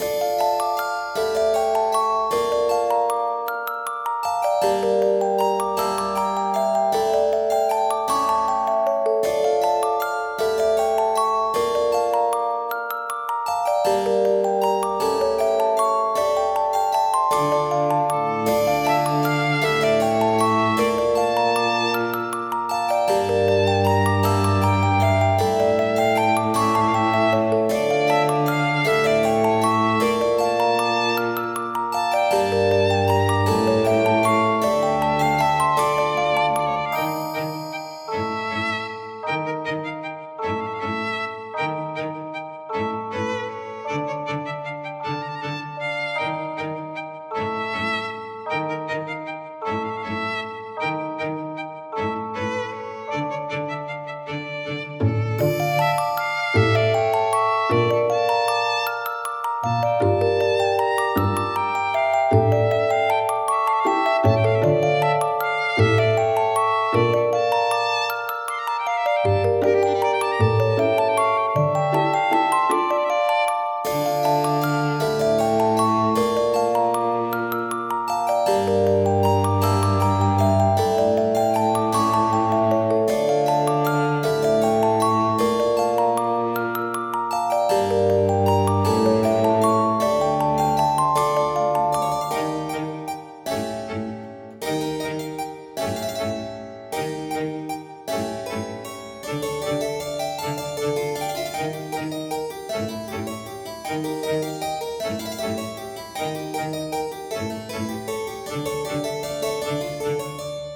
フリーBGM素材- 星の冴えるほどの寒い夜。機械仕掛けの人形とかが踊ったりしているような。